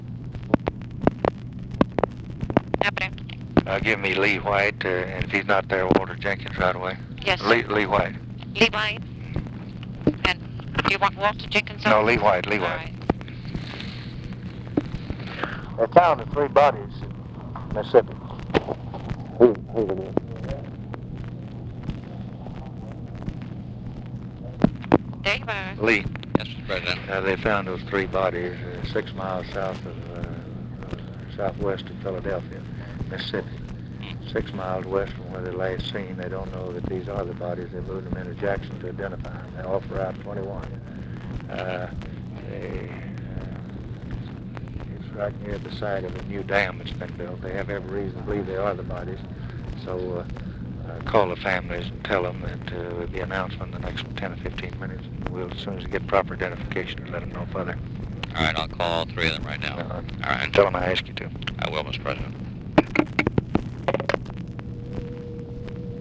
Conversation with OFFICE CONVERSATION